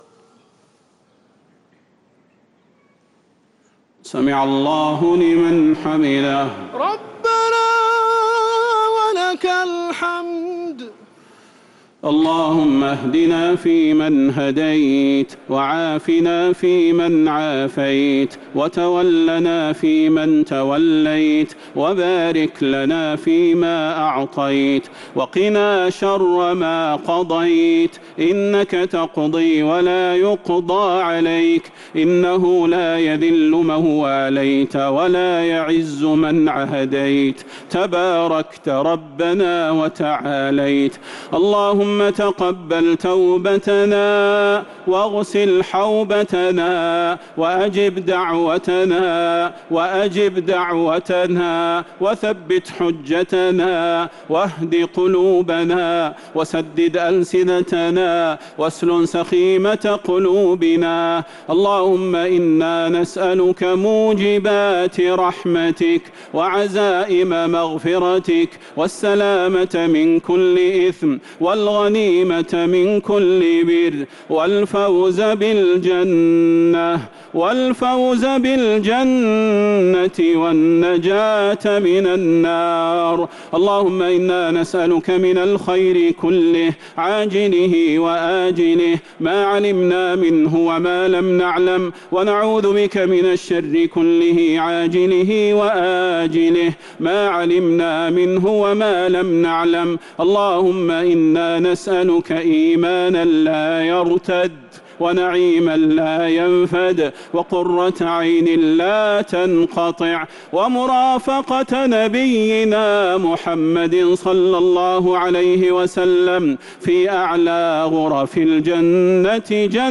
دعاء القنوت ليلة 10 رمضان 1447هـ | Dua 10th night Ramadan 1447H > تراويح الحرم النبوي عام 1447 🕌 > التراويح - تلاوات الحرمين